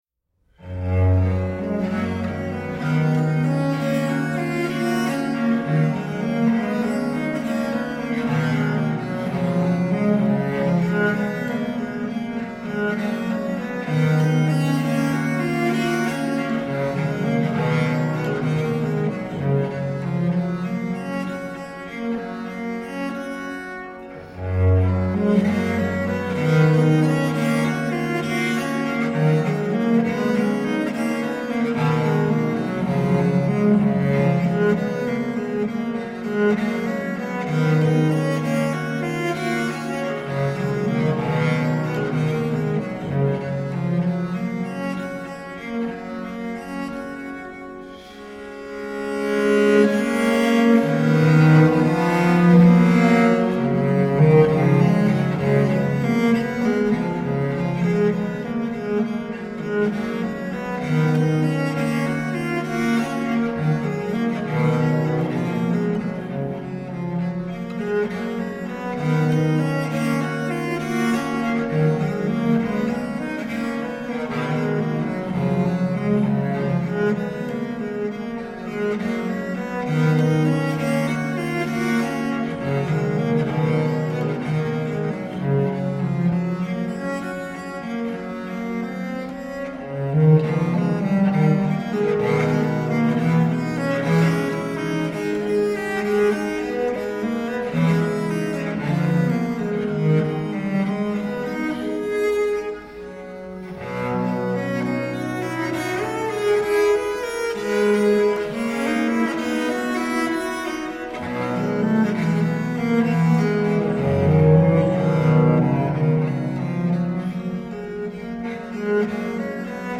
toasty, elegantly merry mood
rich viola de gamba